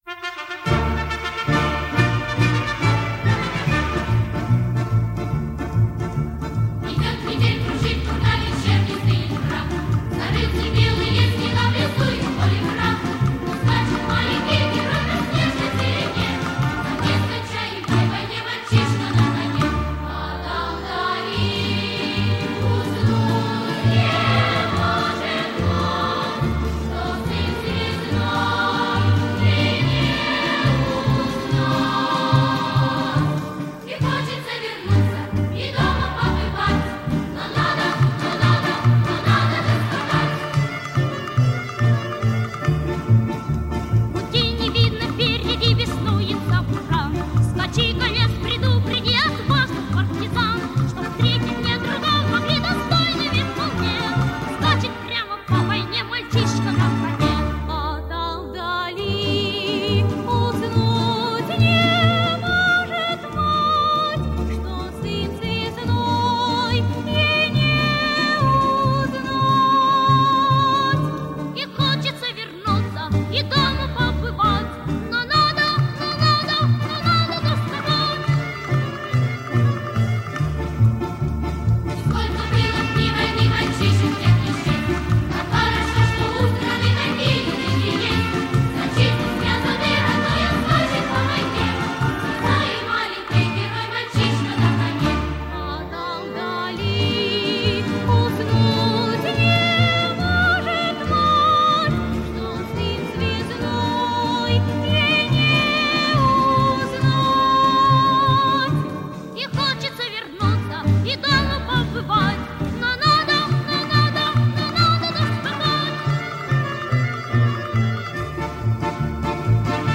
• Категория: Детские песни
советские детские песни, военные песни